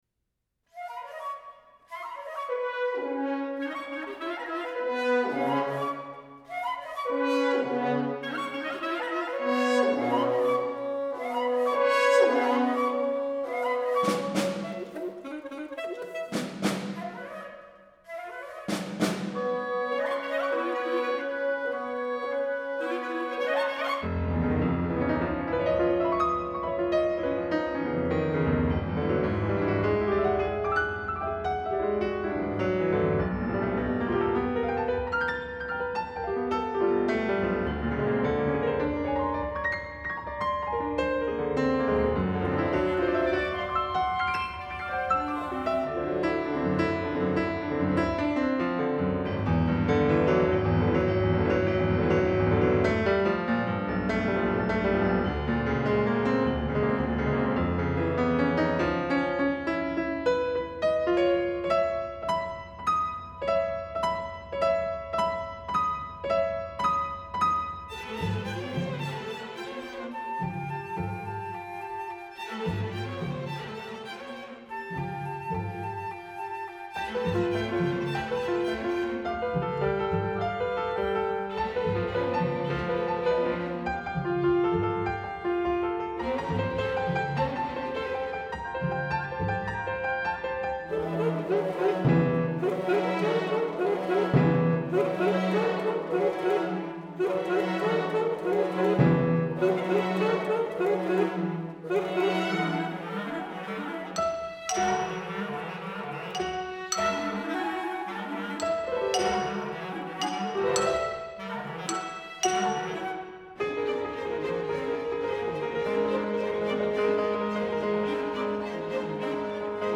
The first speed is Moderato and the second is Fast.